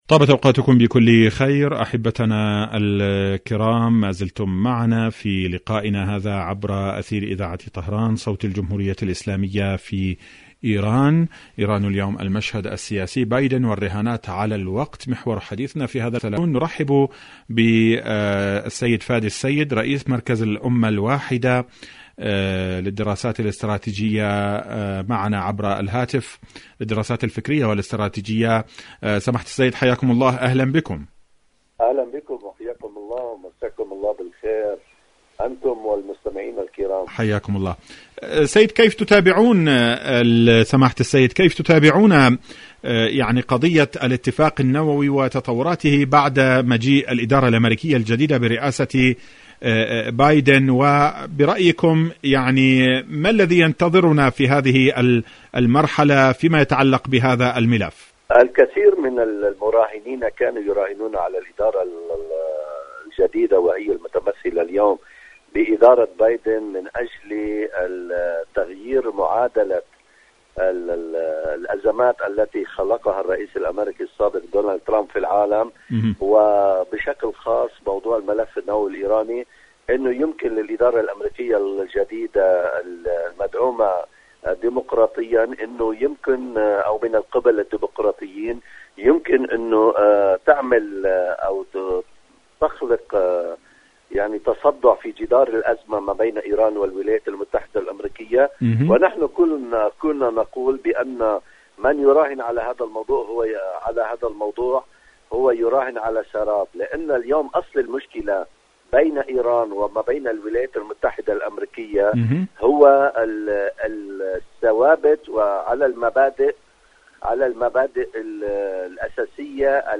إذاعة طهران-إيران اليوم المشهد السياسي: مقابلة إذاعية